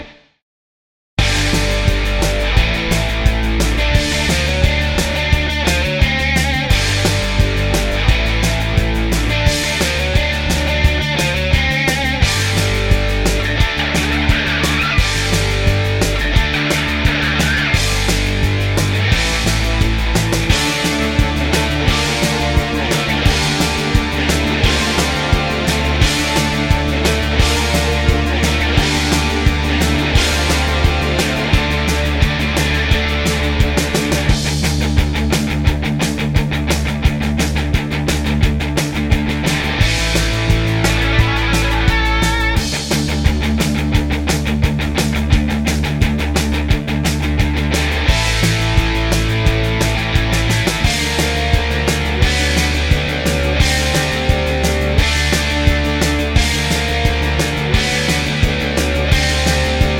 no Backing Vocals Glam Rock 3:16 Buy £1.50